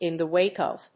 in the wáke of…